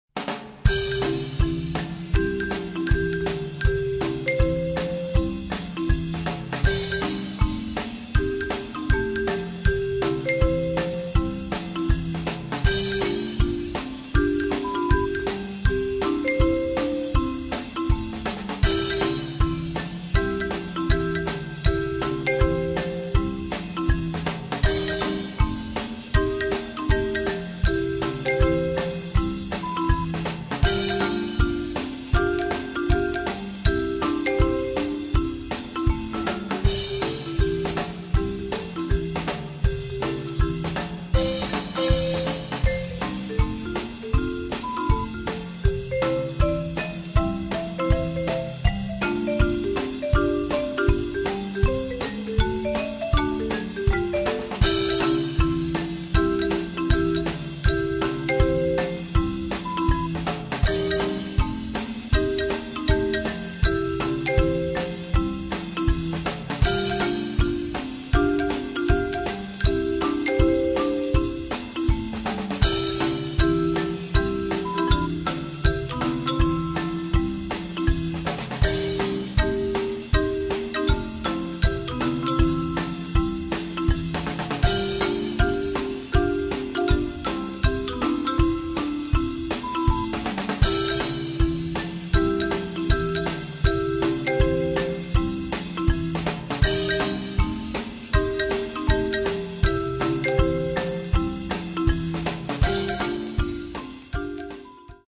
Noten für Schlagzeug/Percussion.